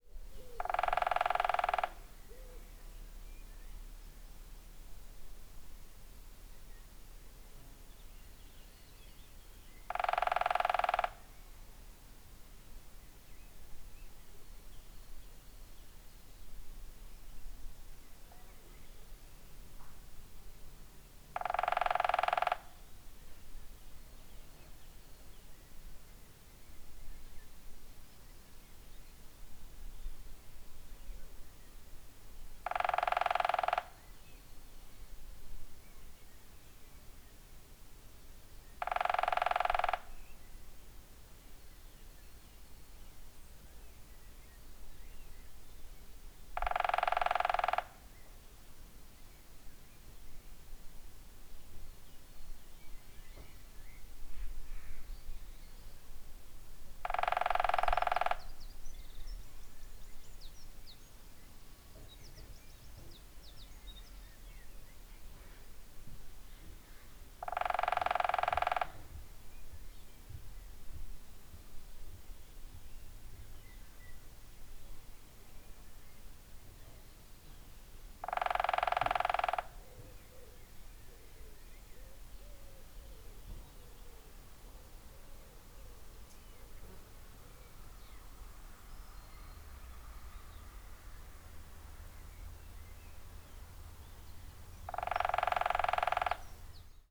woodpeckers